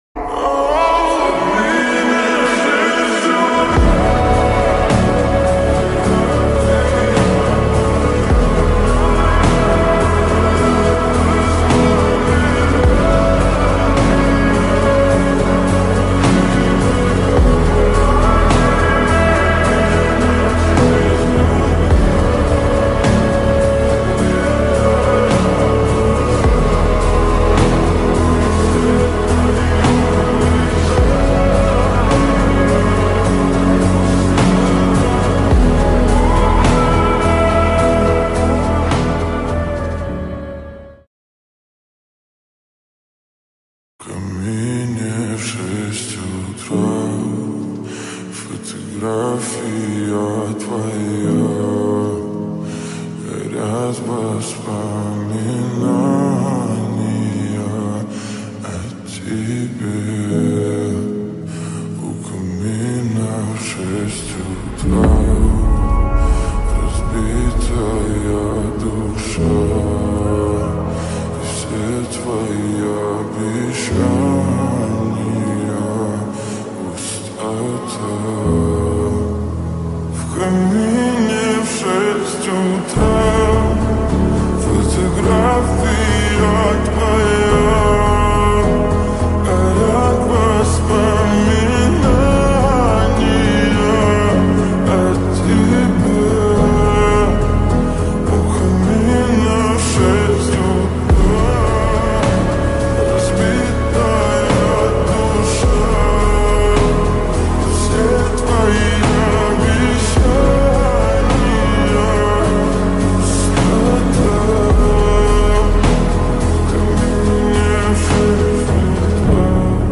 غمگین ترین موزیک
"غمگین"